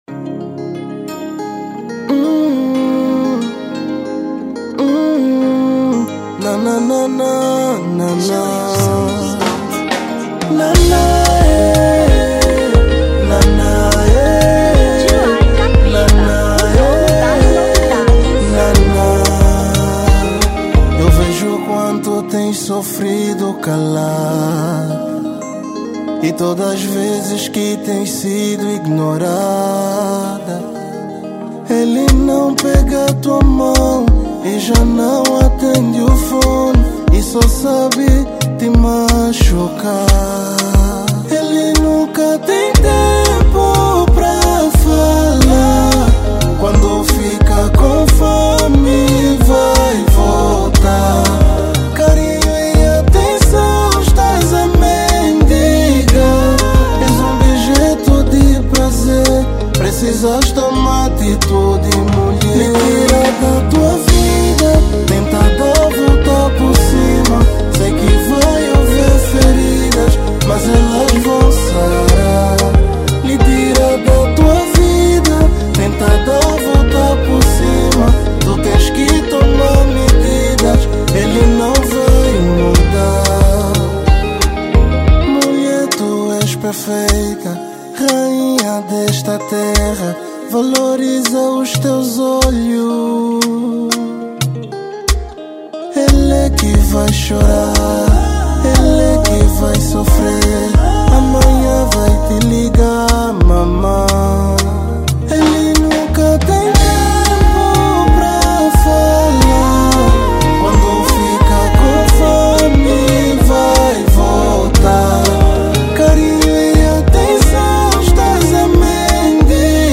Kizomba 2025